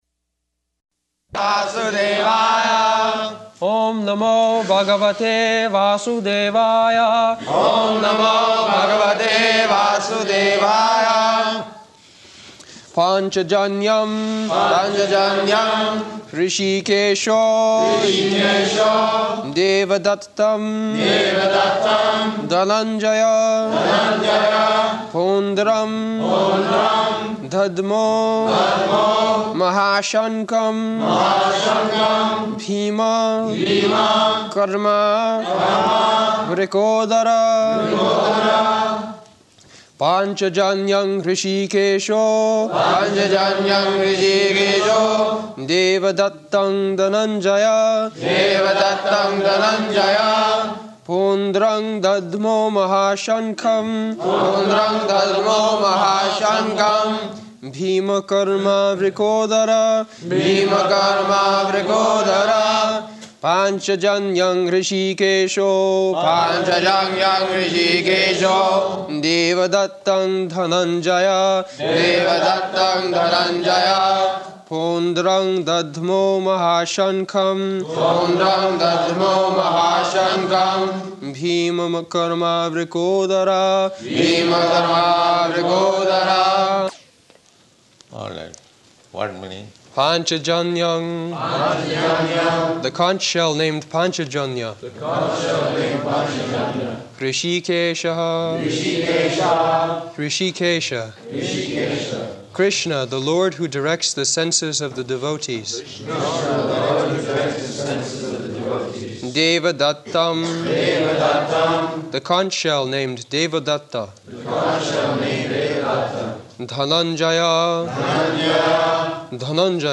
July 15th 1973 Location: London Audio file
[Prabhupāda and devotees repeat] [leads chanting of verse] pāñcajanyaṁ hṛṣīkeśo devadattaṁ dhanañjayaḥ pauṇḍraṁ dadhmau mahā-śaṅkhaṁ bhīma-karmā vṛkodaraḥ [ Bg. 1.15 ] [break] Prabhupāda: All right.